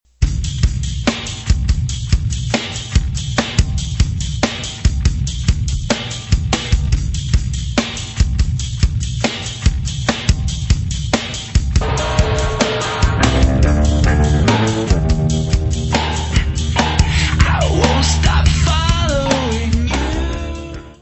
voice/guitar
drums
Turntables
Music Category/Genre:  Pop / Rock